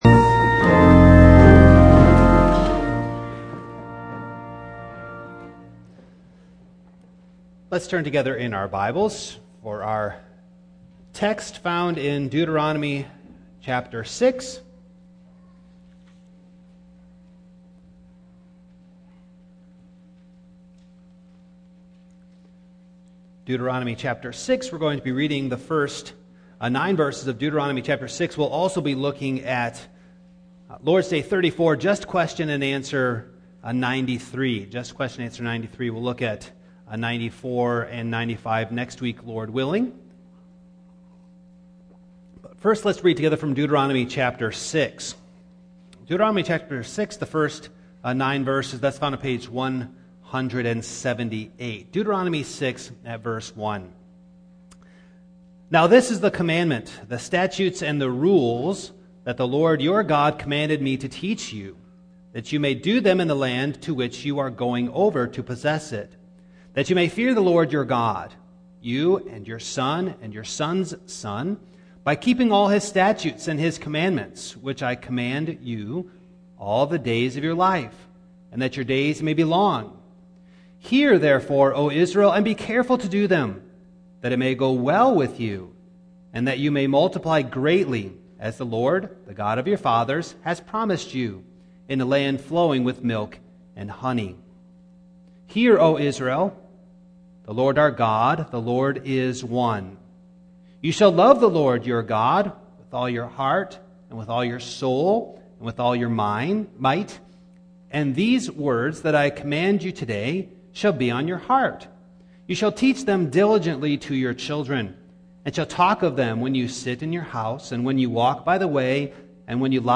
Passage: Deut. 6:1-9 Service Type: Morning